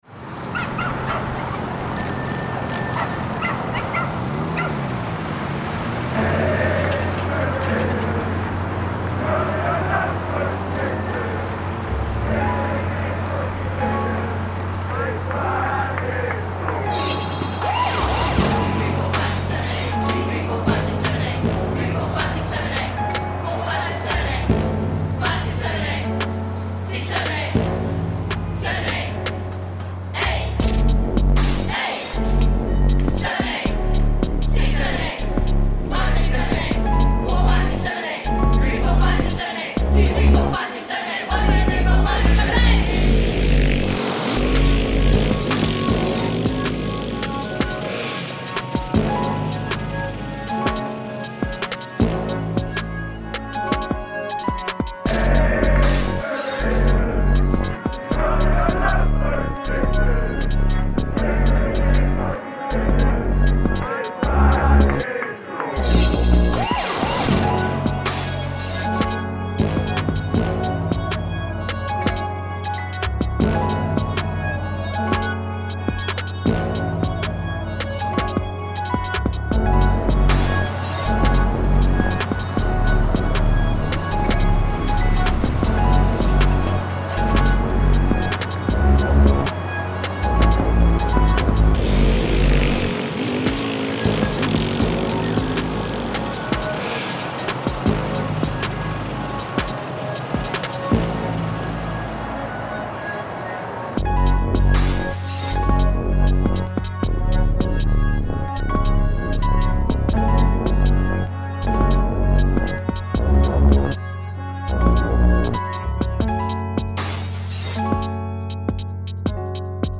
Play the beats below – created by young people and see how the visual image above responds to their sound!
Create-beats-track-1-with-Chant-1.mp3